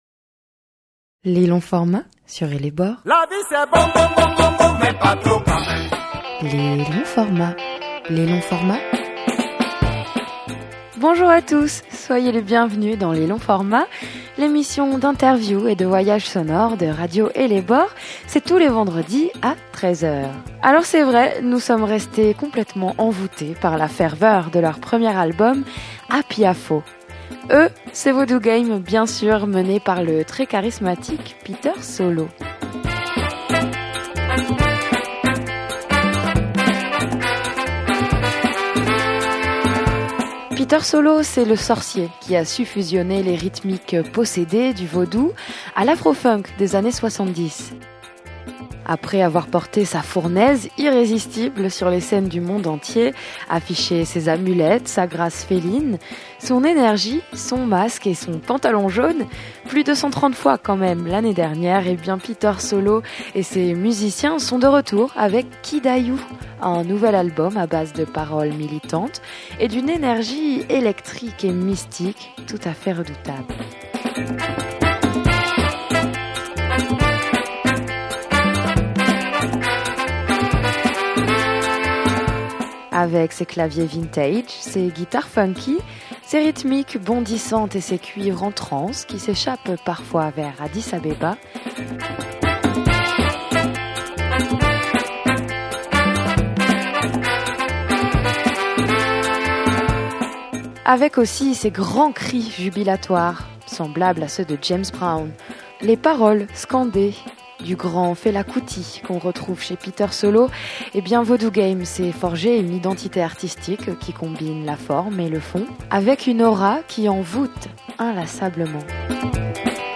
Vaudou Game : l'interview - Radio Ellebore